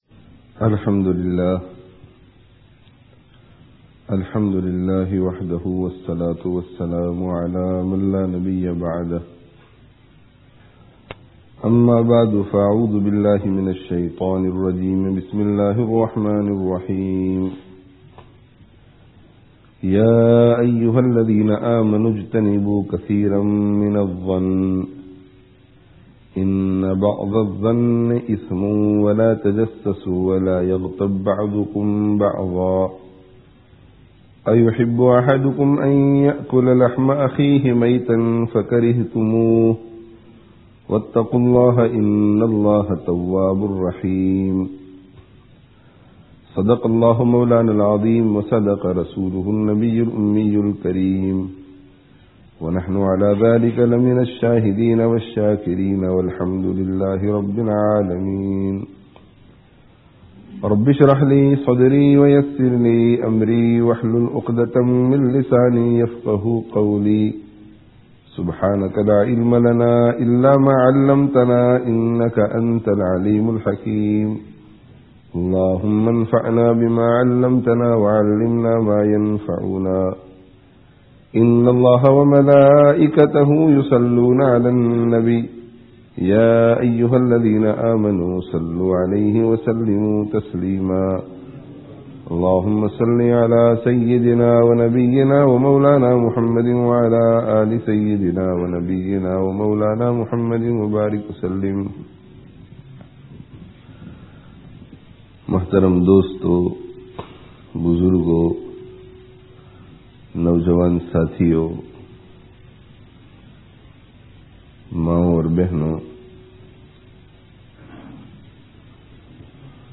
Bad Gumānī - Shaytān kā Eyk Hathyār (Masjid An Noor, Leicester 22/06/07)